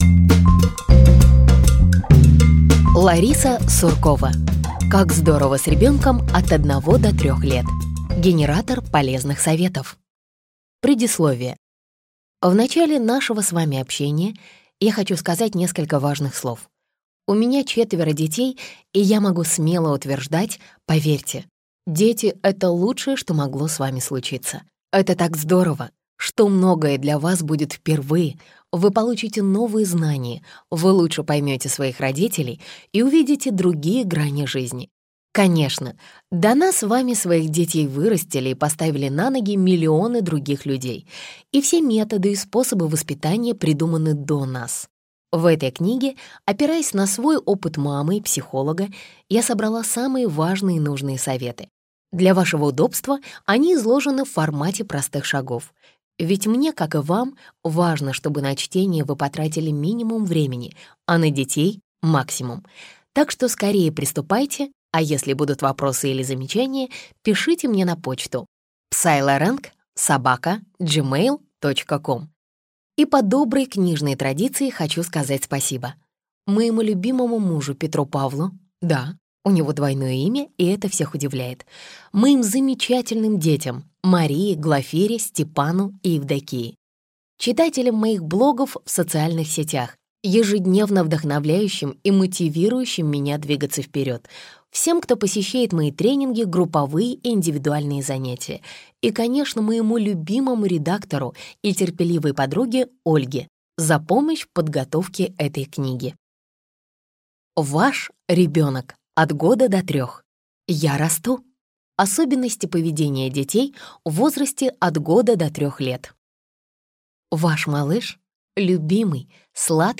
Аудиокнига Как здорово с ребенком от 1 до 3 лет: генератор полезных советов - купить, скачать и слушать онлайн | КнигоПоиск